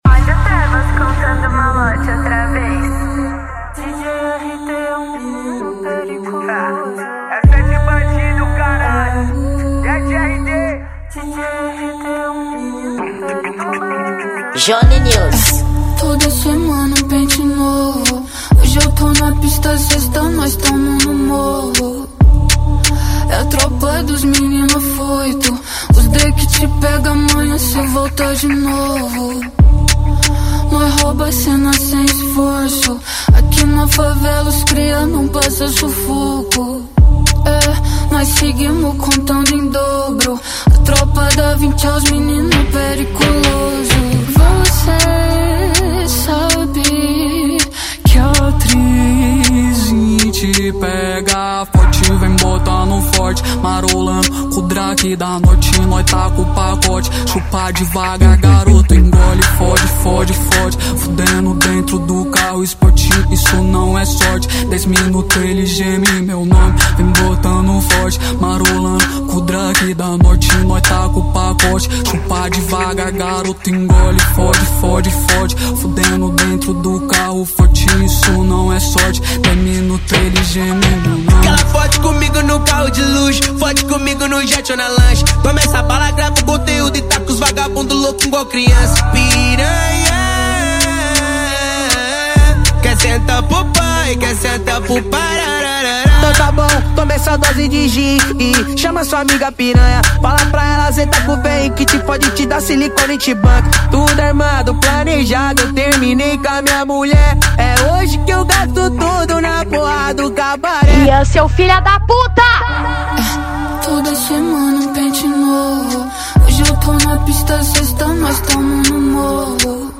Gênero: Funk